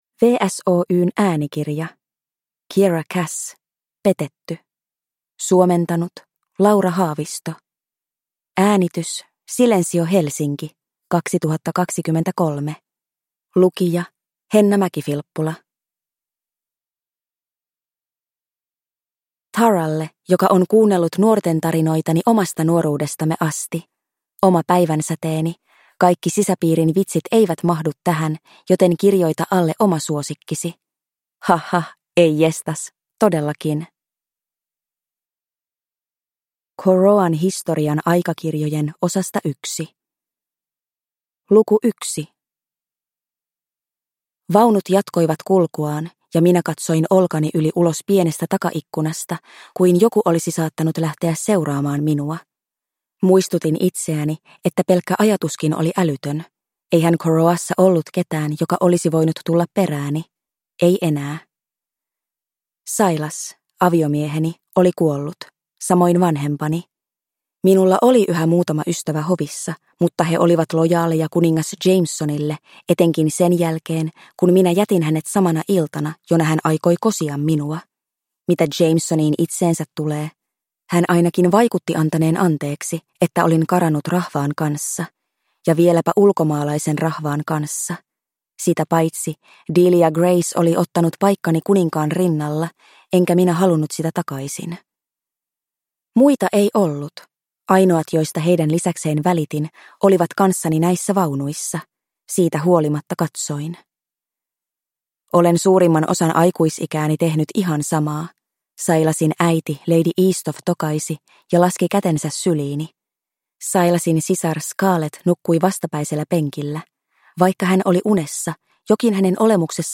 Petetty – Ljudbok